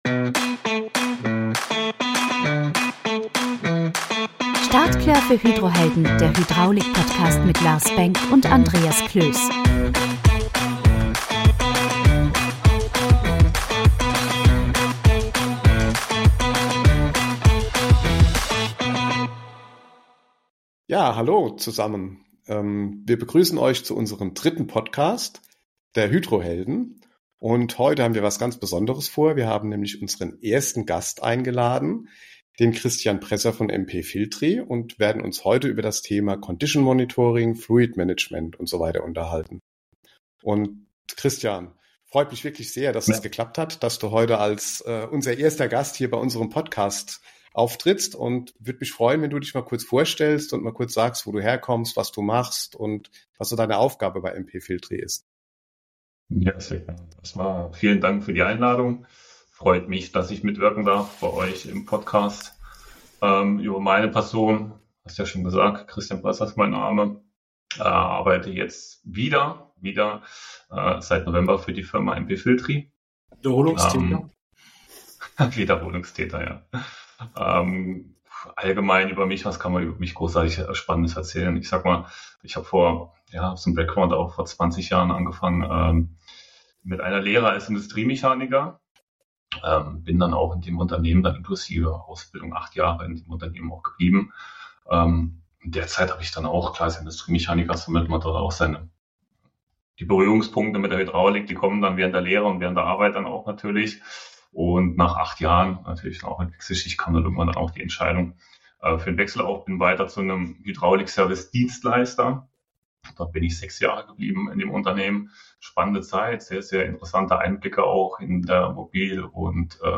Um dieses Thema detailliert zu erkunden, begrüßen wir einen sehr speziellen Gast